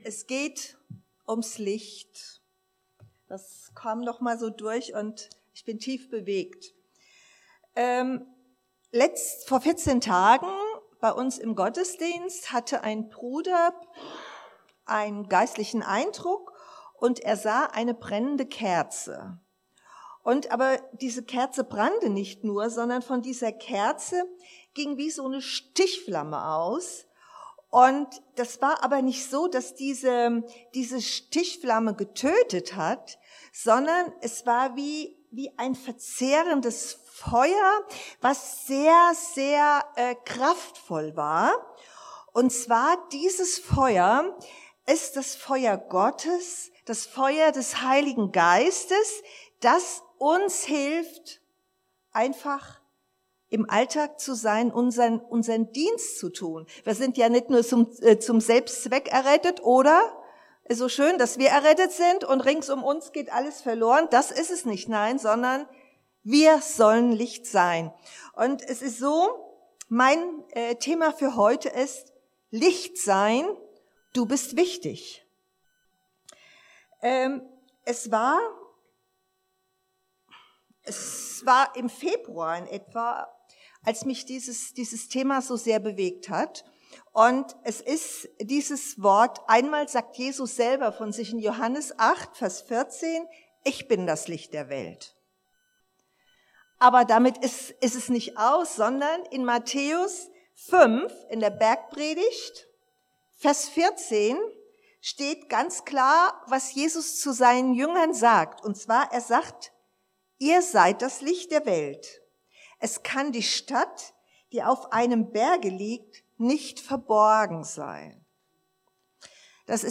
14 Dienstart: Predigt Lichteigenschaft